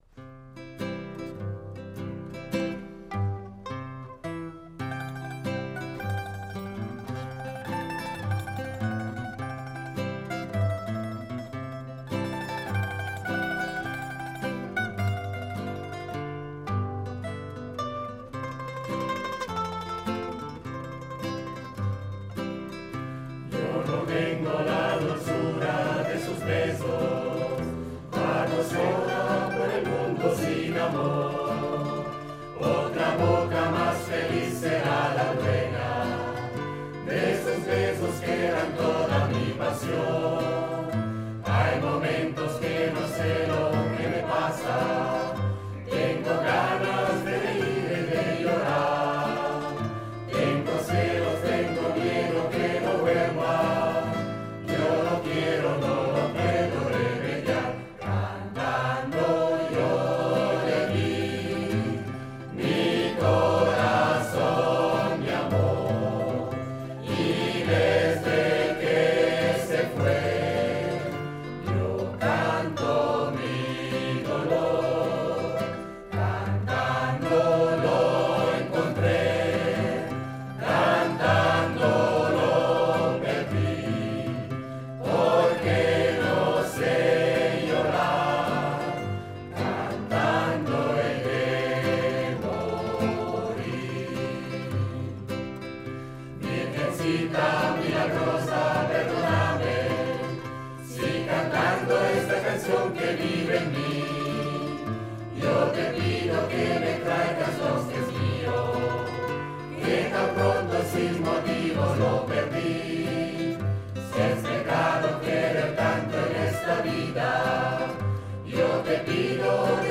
A partire dallo scorso Lunedì di Pasqua la Rete Due della RSI ha scelto di dedicare uno spazio radiofonico più regolare all'ampio repertorio della musica corale, con un momento d'ascolto che vuole dar voce a una realtà molto radicata nella vita sociale e nel panorama artistico della nostra regione.